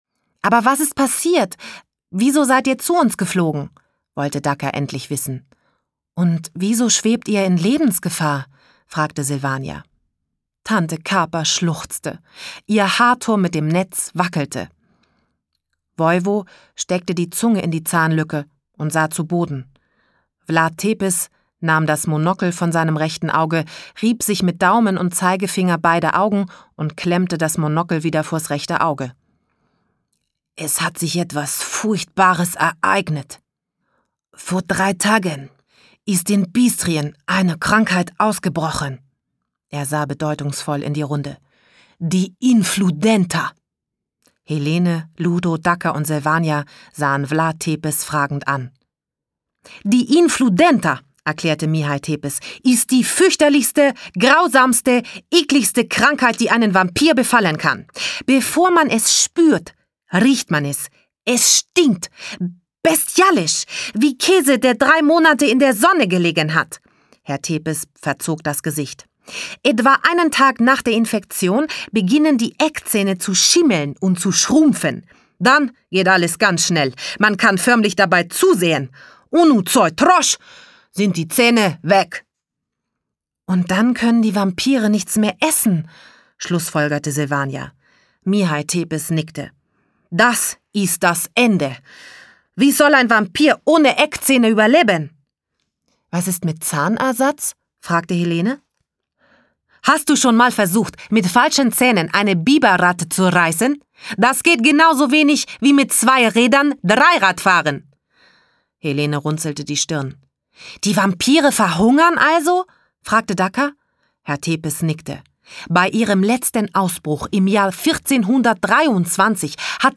Schlagworte Hörbuch für Kinder/Jugendliche • Hörbuch für Kinder/Jugendliche (Audio-CD) • Hörbuch; Hörspiel für Kinder/Jugendliche • Mädchen • Transsilvanien • Vampire • Vampir; Kinder-/Jugendliteratur • Vampir; Kinder-/Jugendliteratur (Audio-CD) • Vampir; Kinder-/Jugendliteratur (Audio-CDs)